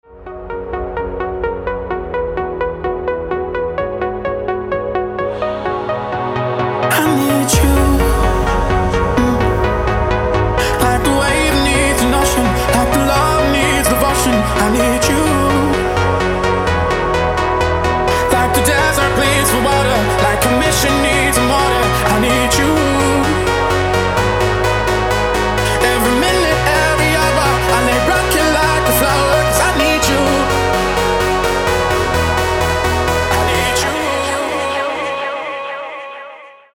• Качество: 256, Stereo
dance
Trance
vocal